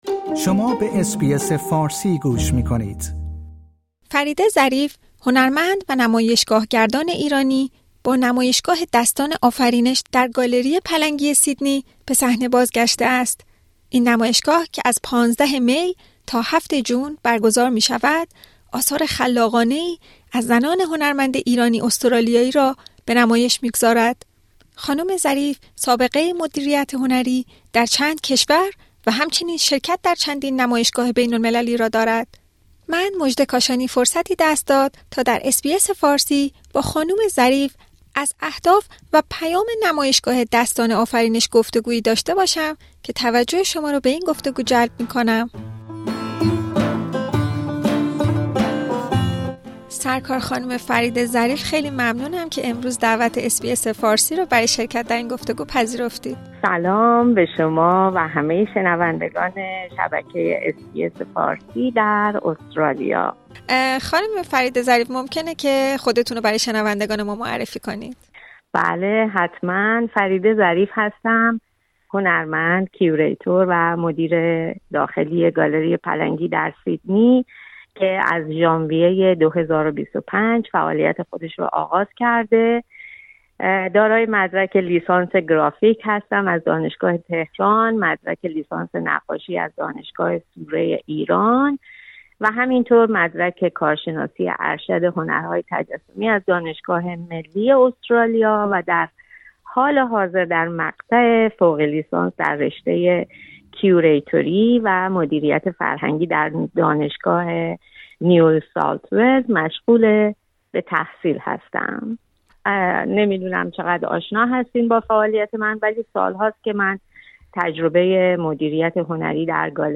گفت‌وگویی با اس‌بی‌اس فارسی